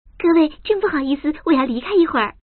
Index of /mahjong_paohuzi_Common_test1/update/1577/res/sfx_pdk/woman/